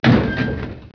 1 channel
eldoor02.wav